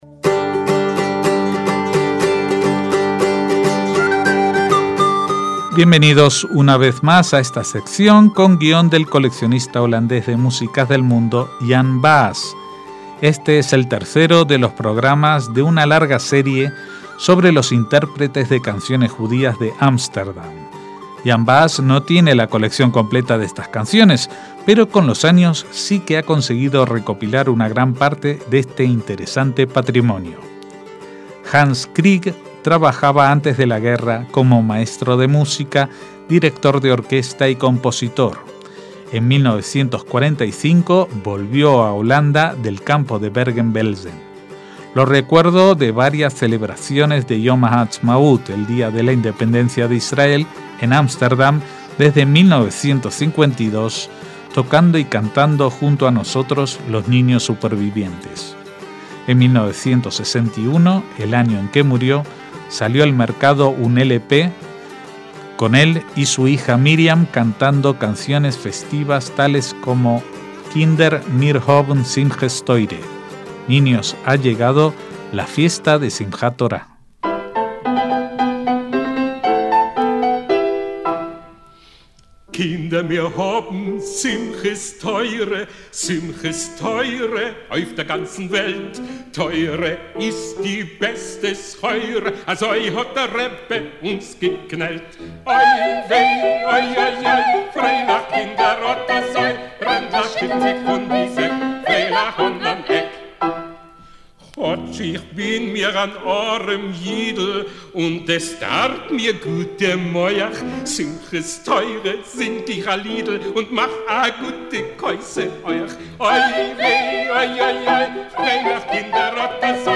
Esta es la tercera de las entregas que dedicaremos a cantantes judíos de Ámsterdam a través de los tiempos.